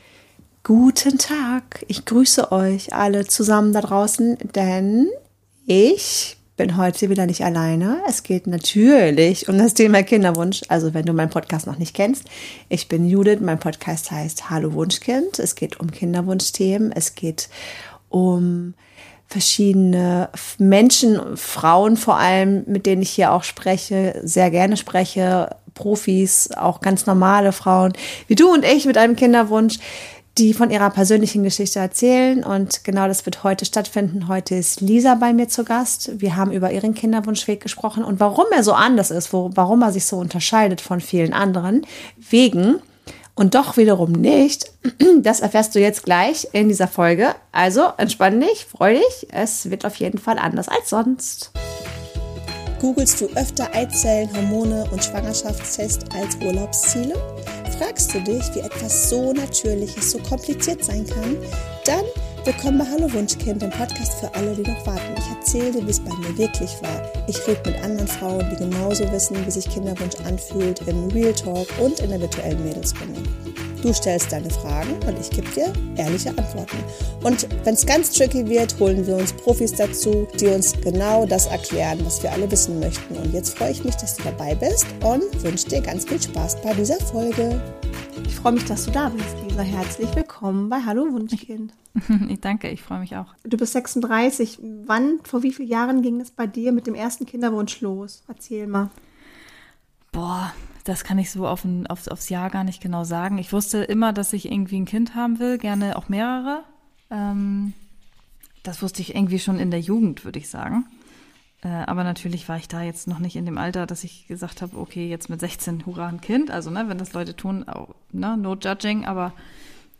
Ein offenes Gespräch über Entscheidungen, Zweifel, Mut und neue Wege zum Wunschkind – eine etwas andere Kinderwunschgeschichte, die zeigt, dass Familien ganz unterschiedlich entstehen können.